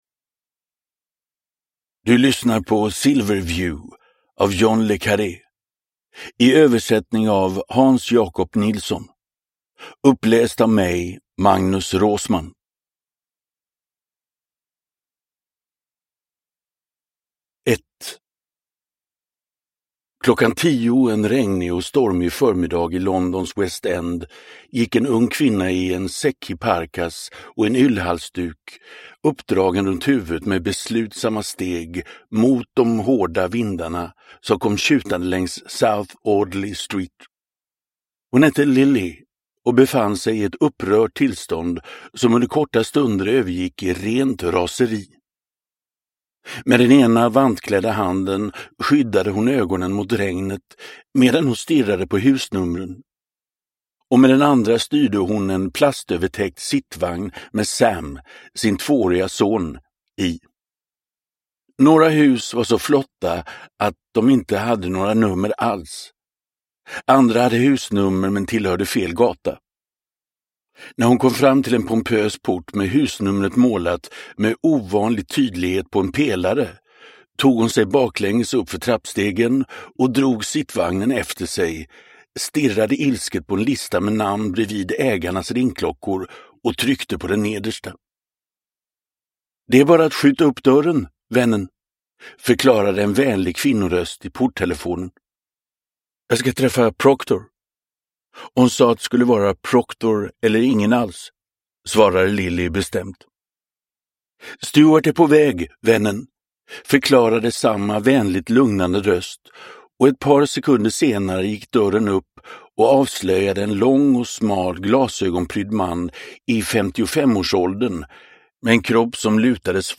Silverview – Ljudbok – Laddas ner